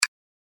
hover.mp3